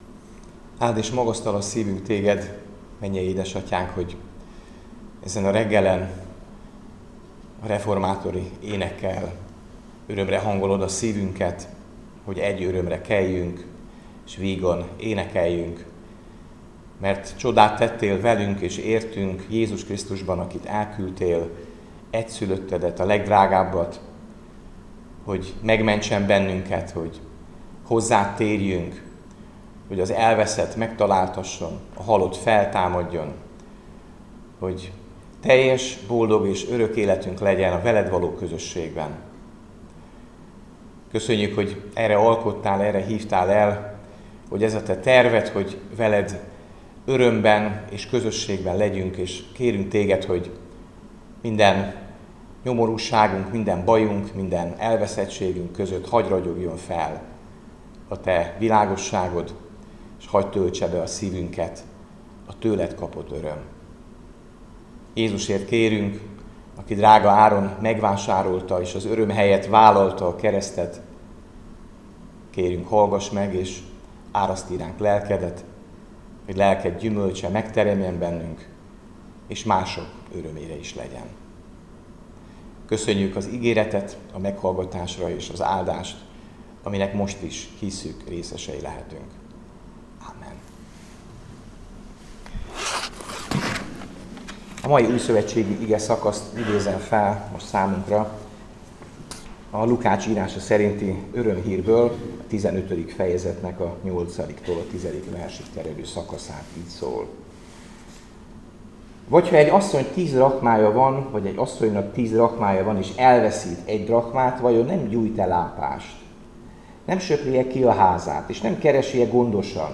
Áhítat, 2025. március 11.